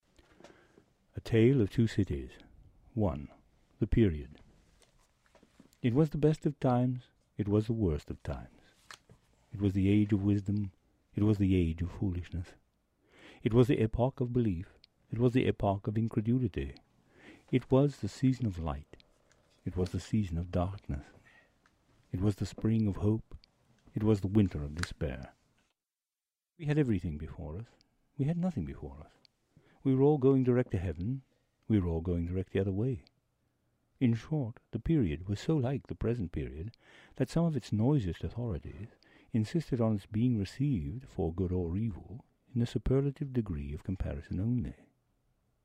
Accents